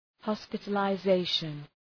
Προφορά
{,hɒspıtələ’zeıʃən}